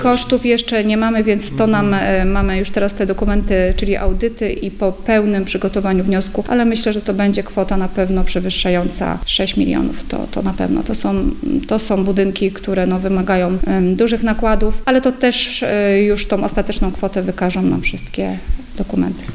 Wiadomości
– To na pewno będzie kwota przewyższająca 6 milionów, ale ostateczna kwota będzie znana po przygotowaniu wszystkich dokumentów – tłumaczy burmistrz Pyrzyc Marzena Podzińska.